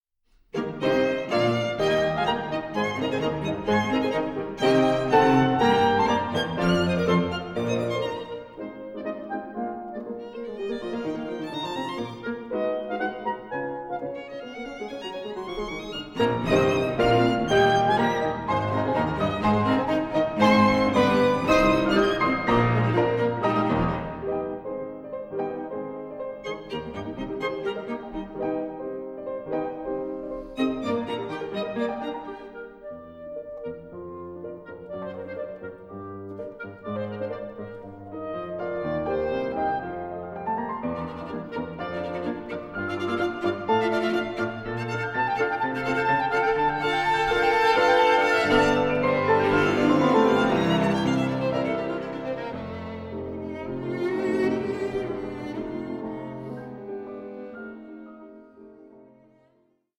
Allegro con brio 6:10